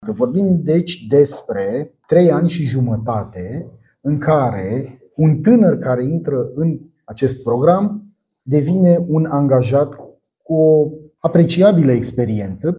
Ministrul muncii, Florin Manole într-o conferință de presă la Arad: